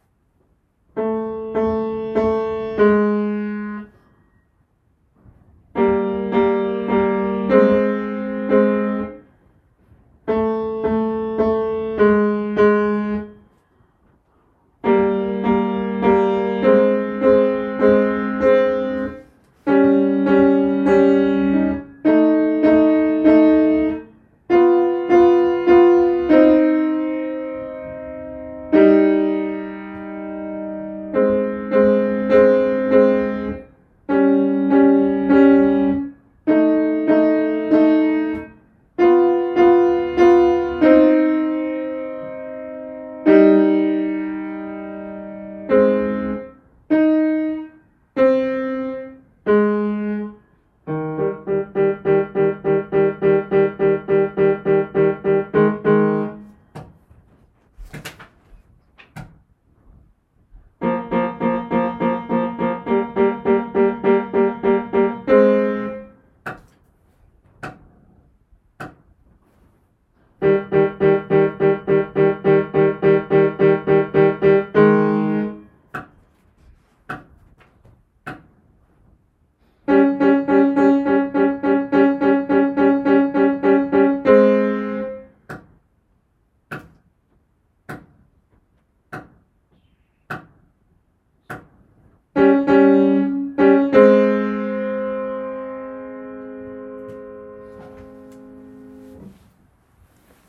音とり音源
ソプラノ